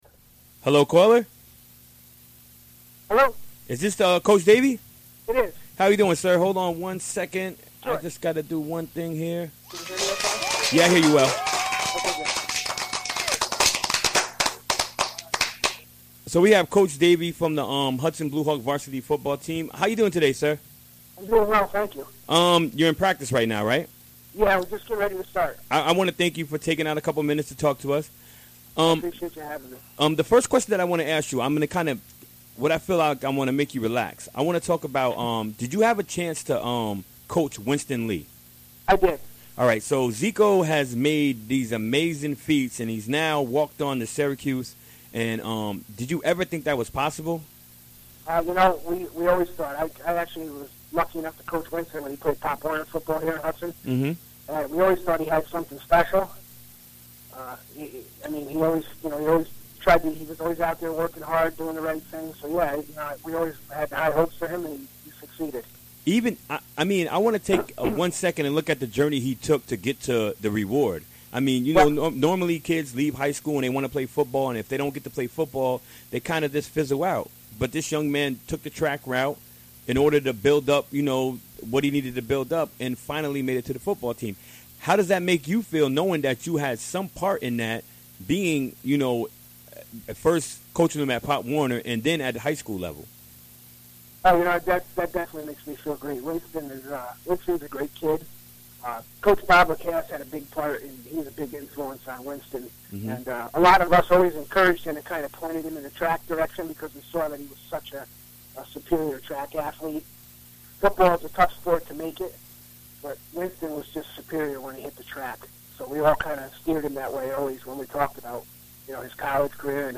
Recorded during the WGXC Afternoon Show Wednesday, August 24, 2016.